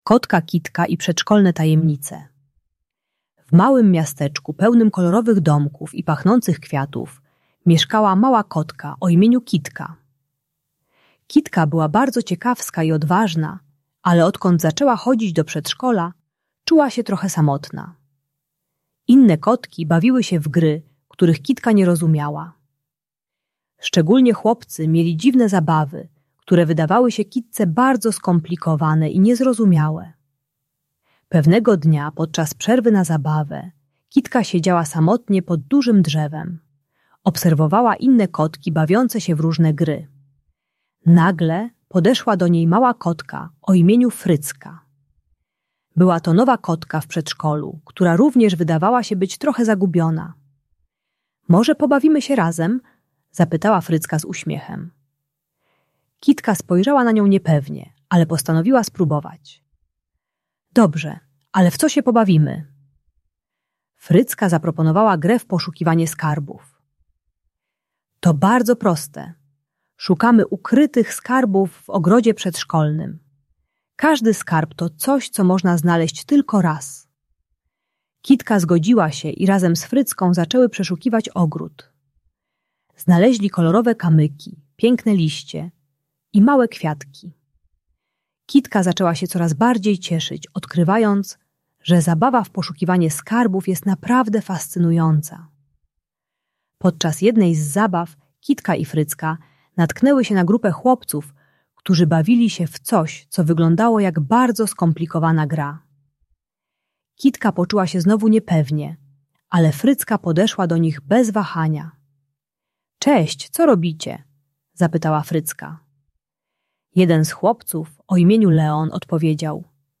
Kotka Kitka i Przedszkolne Tajemnice - Przedszkole | Audiobajka